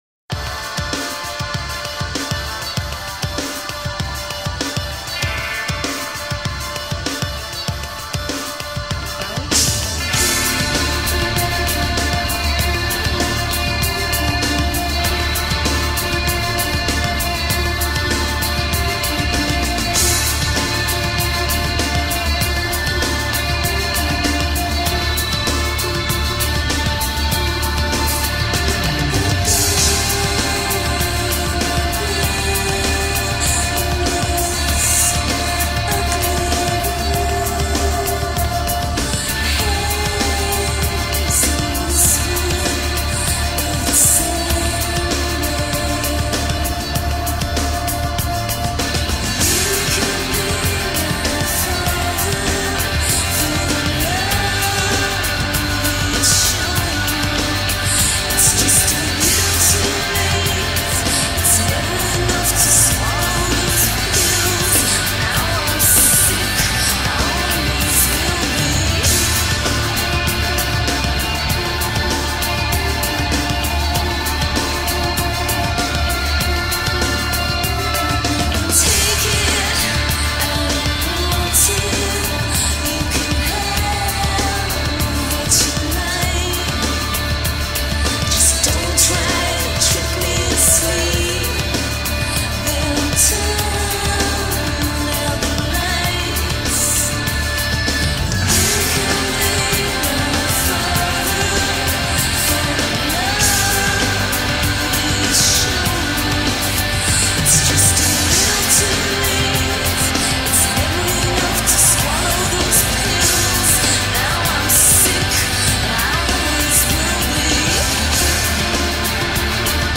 An electronica/shoe-gaze/Dream-Pop/alternative duo